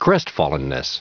Prononciation du mot crestfallenness en anglais (fichier audio)
crestfallenness.wav